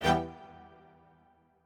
admin-leaf-alice-in-misanthrope/strings34_1_001.ogg at main